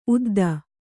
♪ udda